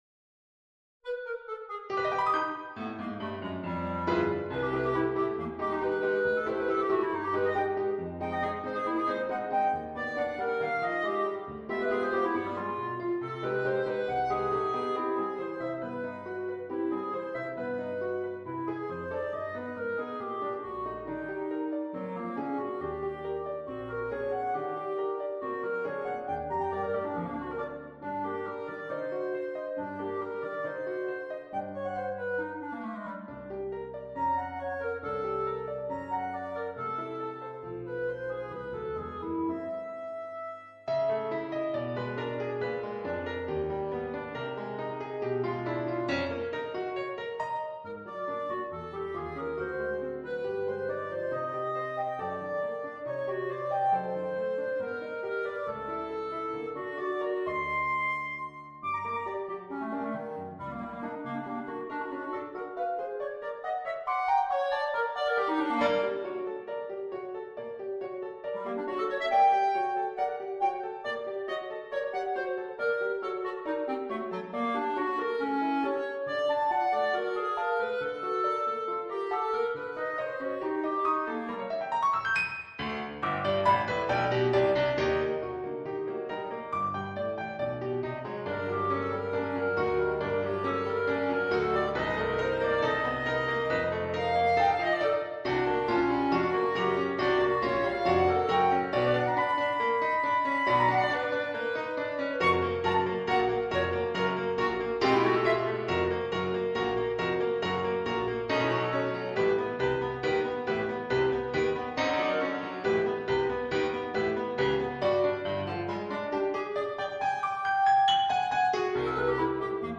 CLARINETTO , E PIANOFORTE , MUSICA DA CAMERA